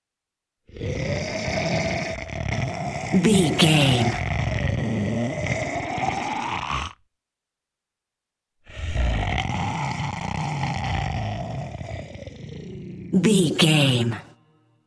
Monster snarl medium creature x2
Sound Effects
ominous
eerie
angry